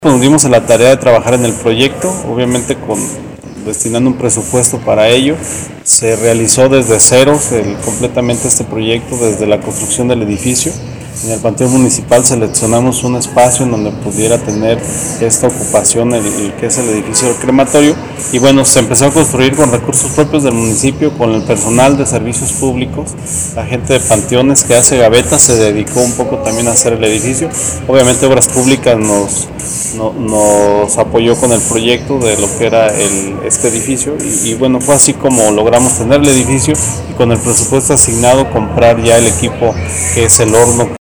AudioBoletines
Rogelio Pérez Espinoza, director de servicios públicos